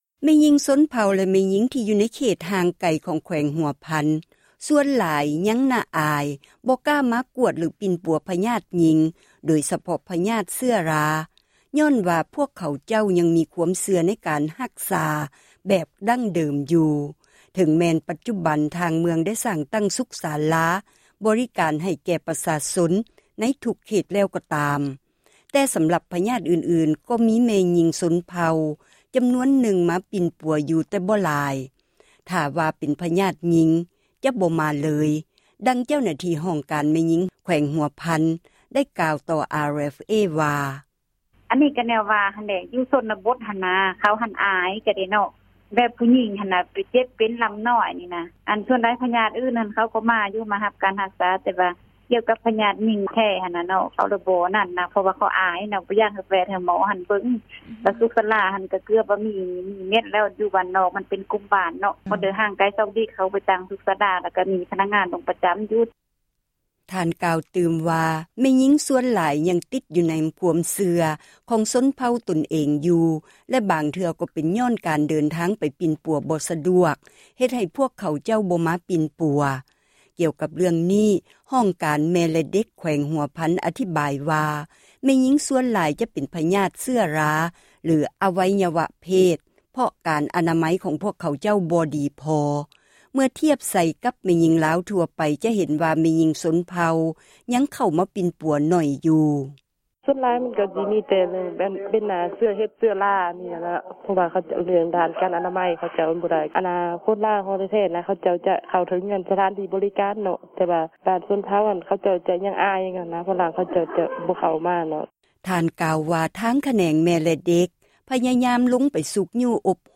ແມ່ຍິງລາວ ຊົນເຜົ່າ ແລະ ແມ່ຍິງ ທີ່ຢູ່ໃນເຂດ ຫ່າງໄກ ຢູ່ແຂວງຫົວພັນ ສ່ວນຫຼາຍ ຍັງອາຍ ທີ່ຈະໄປກວດ ຫຼື ປິ່ນປົວ ນໍາທ່ານໝໍ ໂດຍສະເພາະ ພຍາດເຊື້ອຣາ ຍ້ອນວ່າ ພວກເຂົາເຈົ້າ ຍັງມີ ຄວາມເຊື່ອ ໃນການ ຮັກສາ ແບບດັ່ງເດີມຢູ່ ເຖິງວ່າ ປັດຈຸບັນ ທາງເມືອງ ໄດ້ຕັ້ງ ສຸຂສາລາ ບໍຣິການ ໃຫ້ແກ່ ປະຊາຊົນ ໃນ ທຸກເຂດແລ້ວ. ແຕ່ສໍາລັບ ພຍາດອື່ນໆ ກໍມີແມ່ຍິງ ຊົນເຜົ່າ ຈໍານວນນຶ່ງ ໄປປິ່ນປົວຢູ່ ແຕ່ບໍ່ຫຼາຍ. ຖ້າວ່າເປັນ ພຍາດແມ່ຍິງ ກໍຈະບໍ່ໄປ ຫາໝໍເລີຍ, ດັ່ງ ເຈົ້າໜ້າທີ່ ຫ້ອງການ ແມ່ຍິງ ແຂວງຫົວພັນ ໄດ້ກ່າວຕໍ່ ວິທຍຸ ເອເຊັຽເສຣີ ວ່າ.